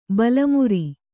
♪ bala muri